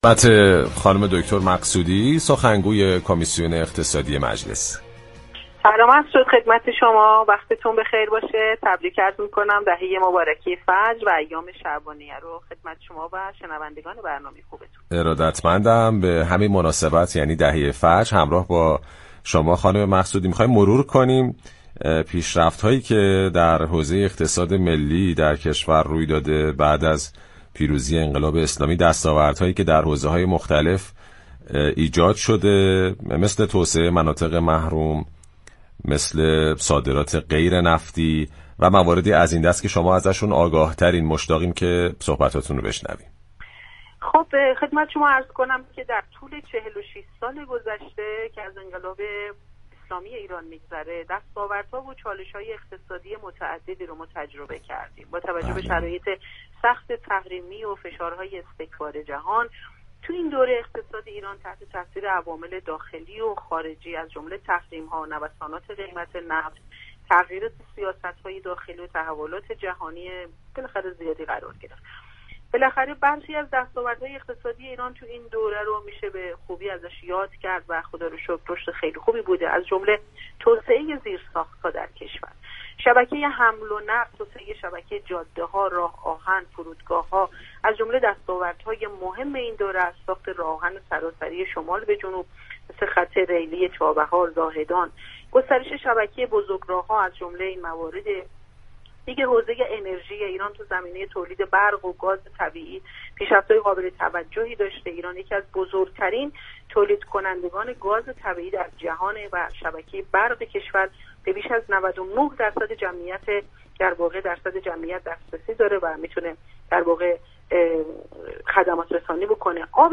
به گزارش پایگاه اطلاع رسانی رادیو تهران، فاطمه مقصودی سخنگوی كمیسیون اقتصادی مجلس در گفت و گو با «بازار تهران» اظهار داشت: در طول 46 سال پس از پیروزی انقلاب اسلامی دستاوردها و چالش‌های اقتصادی متعددی را تجربه كردیم.